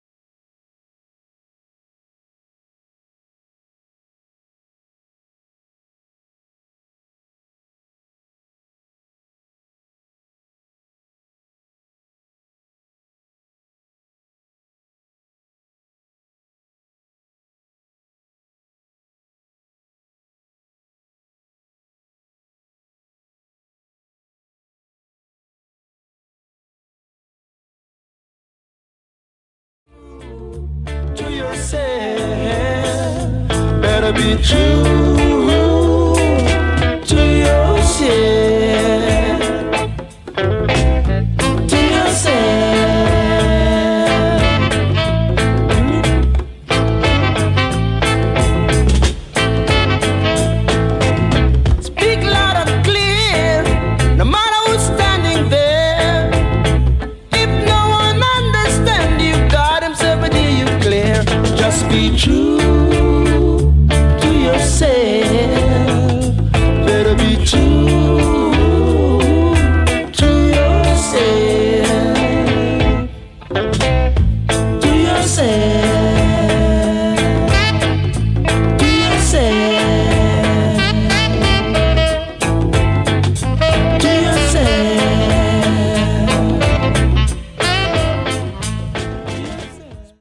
Funky reggae two tracker reissue A